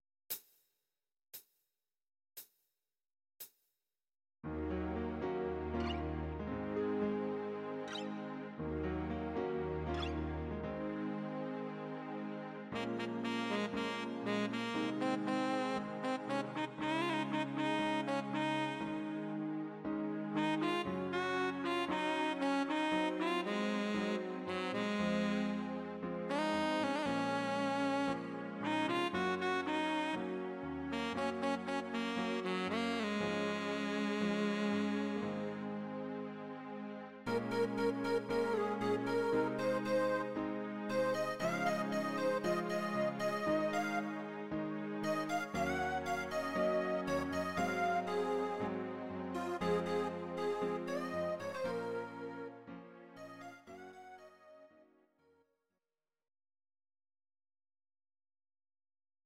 Audio Recordings based on Midi-files
Pop, Duets, 2000s